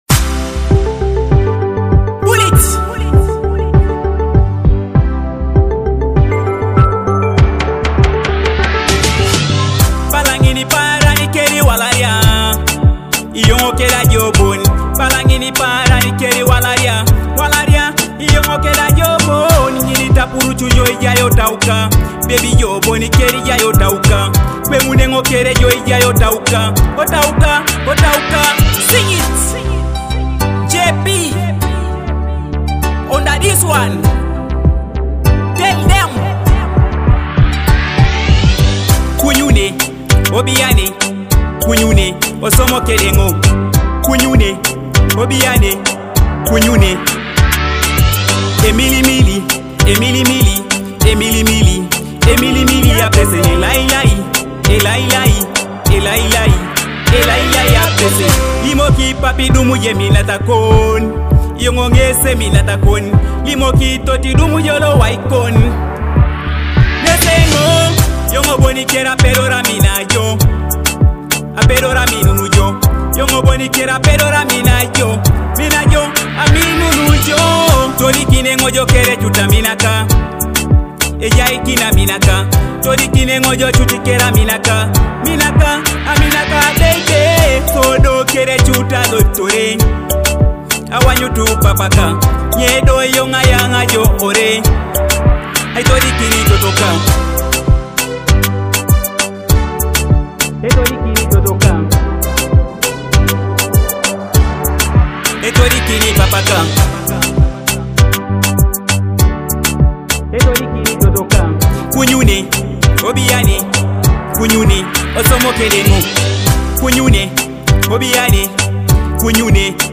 vibrant Teso music hit